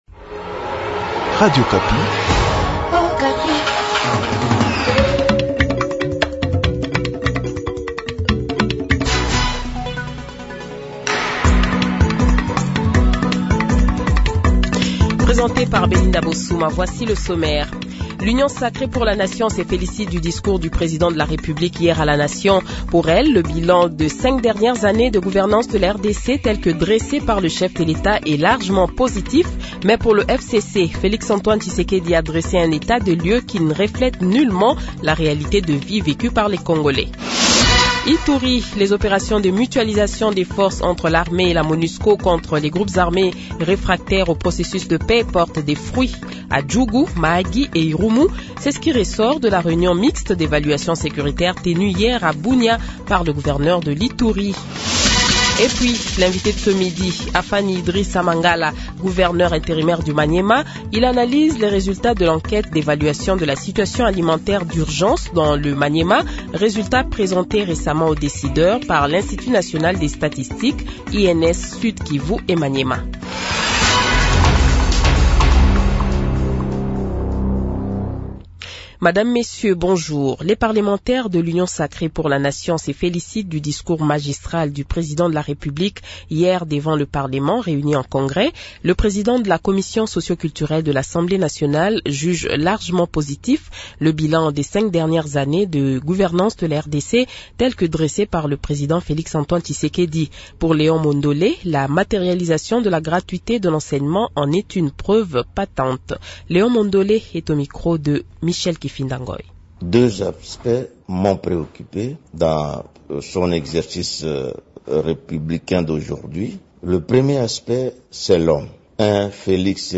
Le Journal de 12h, 15 Novembre 2023 :